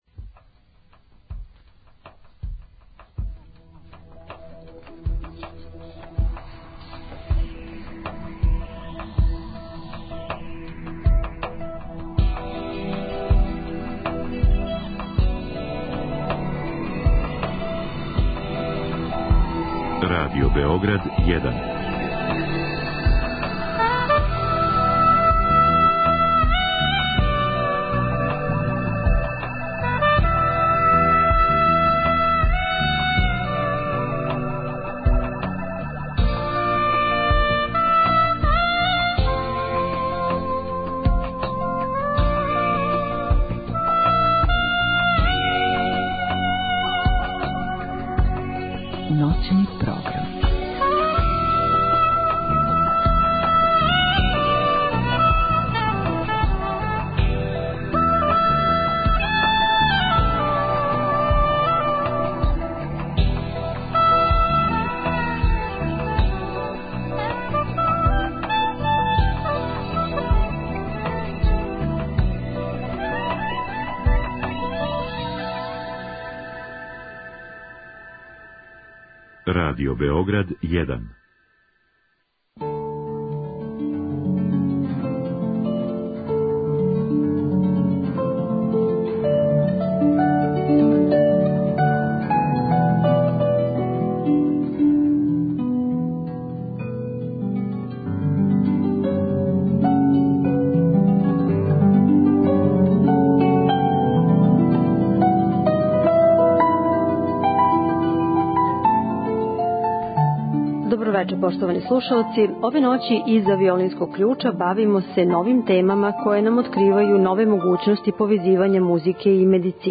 Ноћни програм отвара теме које повезују музику и медицину. Из студија Радио Београда 1 у Врњачкој Бањи разговарамо о значају едукације пацијената о дијабетесу, физикалној терапији, могућностима примене музике у бањском туризму и велнесу као стилу живота.